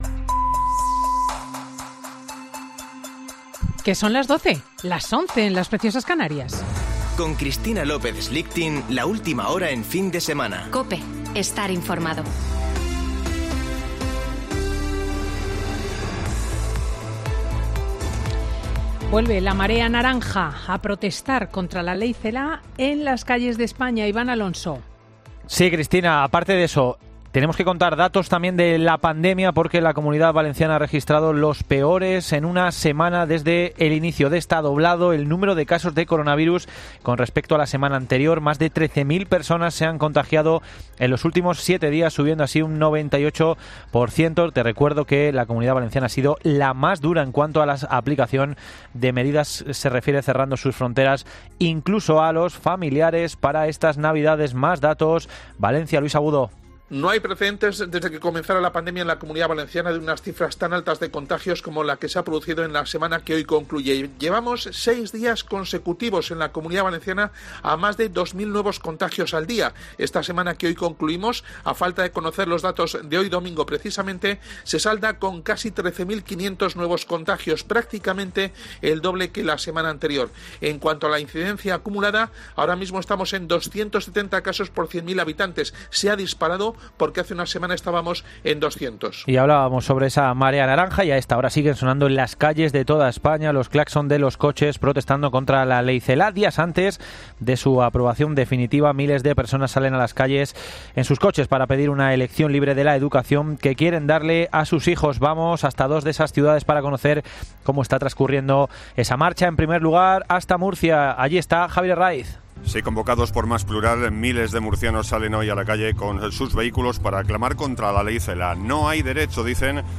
AUDIO: Boletín de noticias de COPE del 20 de diciembre de 2020 a las 12.00 horas